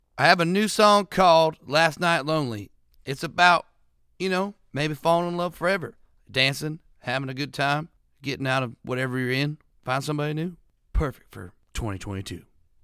Audio / Jon Pardi talks about his new single, "Last Night Lonely."
Jon-Pardi-Last-Night-Lonely.mp3